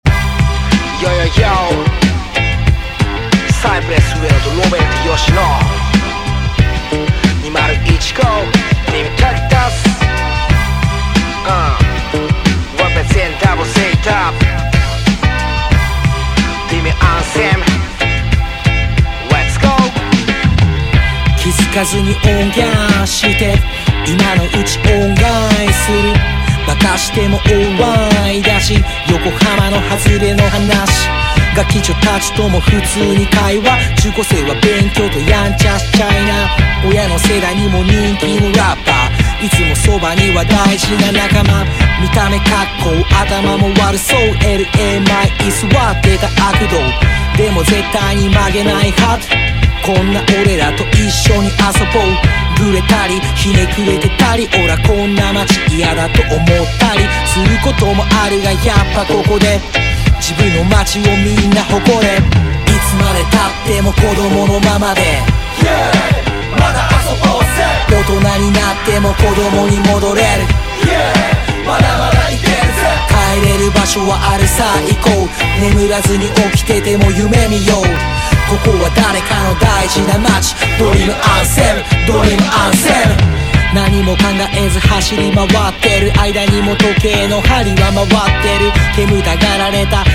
JAPANESE HIP HOP / JAPANESE PUNK
国産ミクスチャー・ロック！